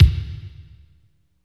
28.07 KICK.wav